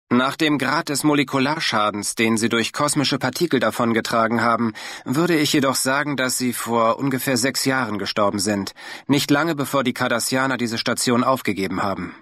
... with the original voices of the Deep Space Nine TV serial ...